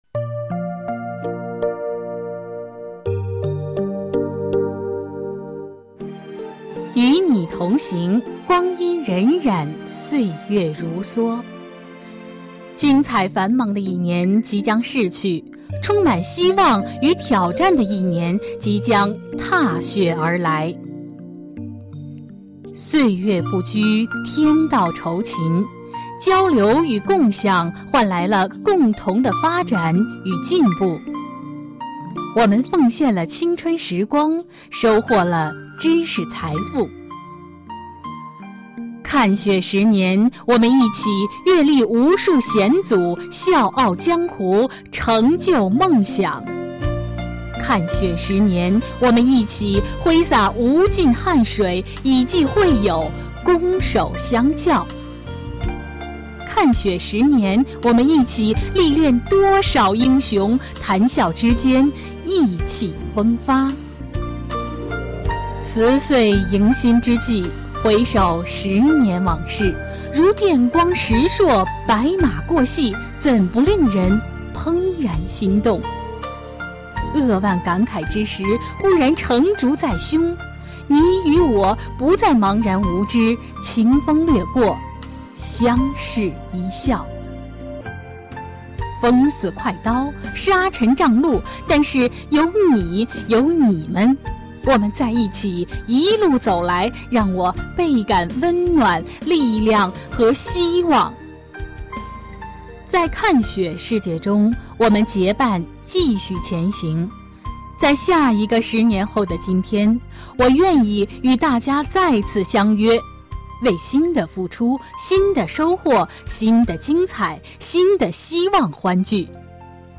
配音朗读
与你同行朗读配音